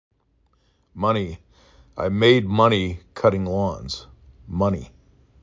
uh = sun, from
E = he, see